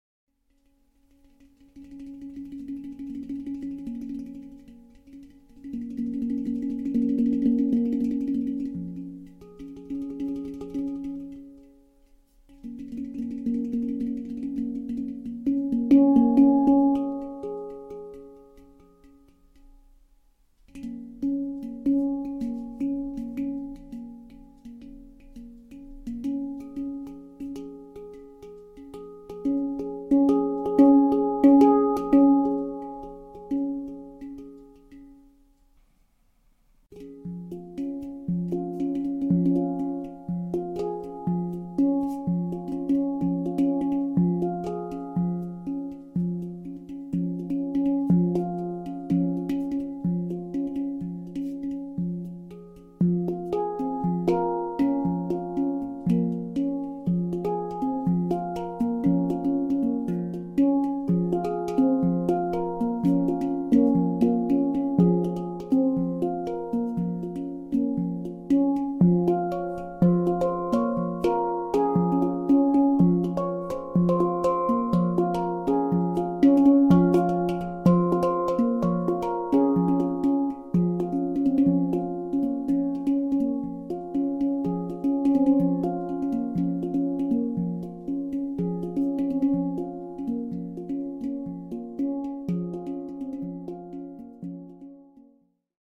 1e generatie hang (2005) horizontaal gespeeld op schoot
Hang_2005_horizontal.mp3